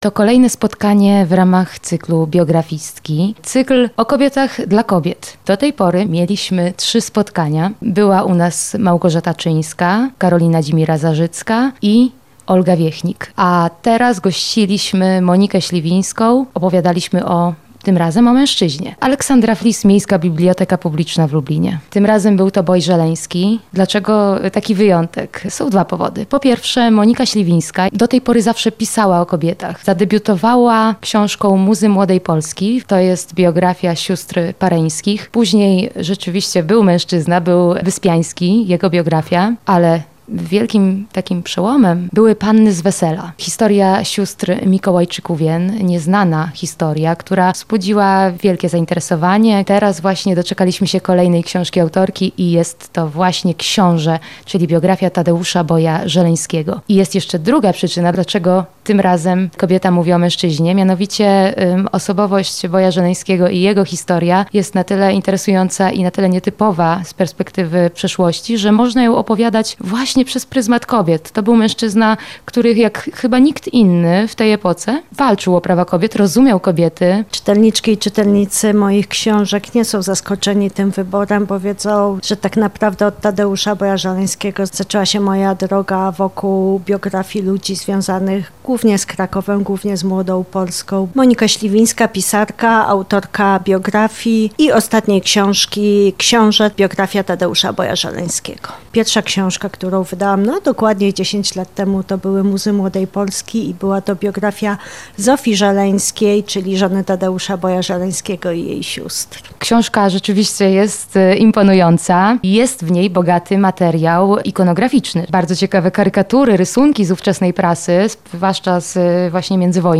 Kolejne spotkanie z cyklu „Biografistki” odbyło się w Lublinie.